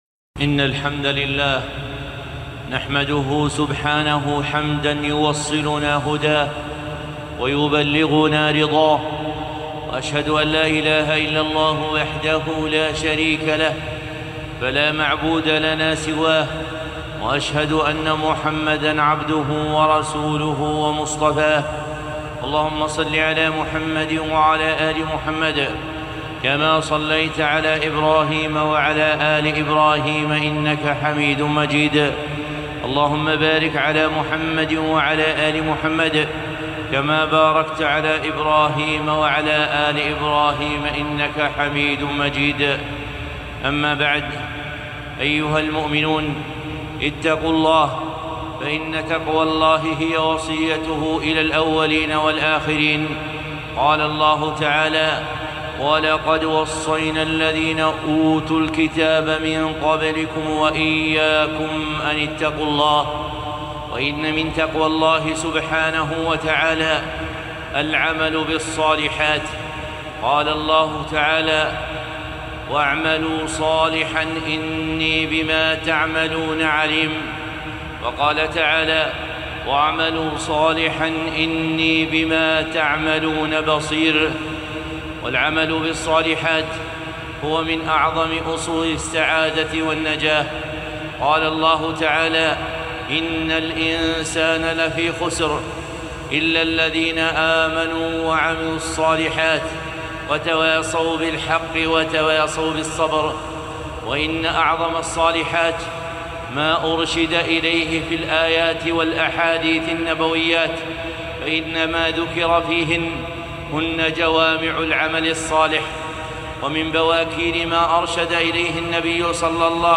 خطبة - اعملوا صالحا